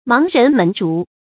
盲人捫燭 注音： ㄇㄤˊ ㄖㄣˊ ㄇㄣˊ ㄓㄨˊ 讀音讀法： 意思解釋： 見「盲翁捫鑰」。